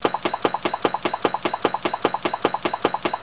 clankmachine.wav